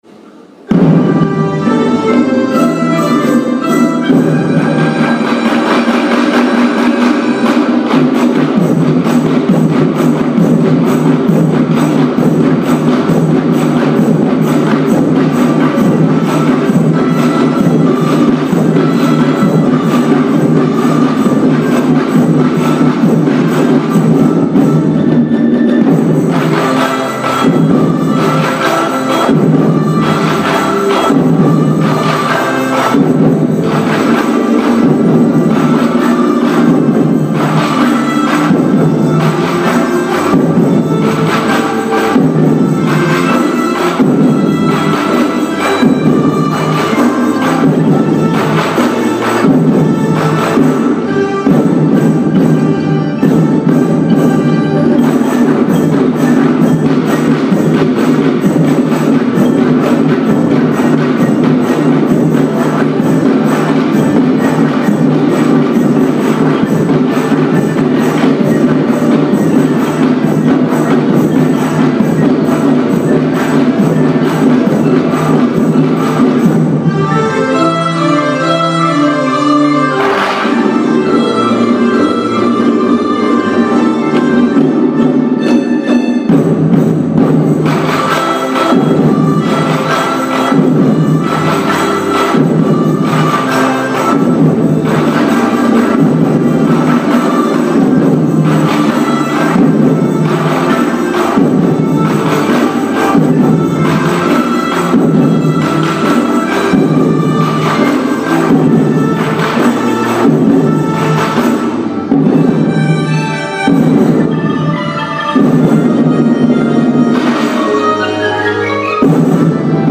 2016年6月4日（土）ふれあい科　１１ｔｈ大空創立記念コンサート
ソーラン節」は会場中のみんなの視線を釘づけにした圧巻の演奏でした！
アンコールの大合唱！
アンコールの演奏は、さらに迫力を増し、見事に会場の思いに応えたものでした♪